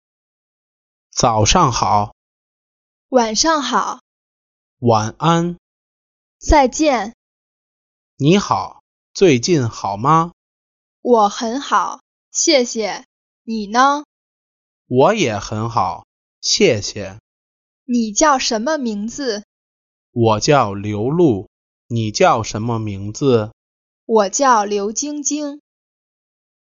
A simple dialogue introducing some common greetings and salutations.